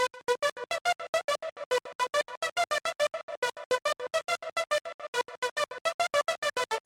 新时代合成器
描述：ChillStep合成器，节奏感强，很有新时代气息
Tag: 140 bpm Chill Out Loops Synth Loops 1.15 MB wav Key : Unknown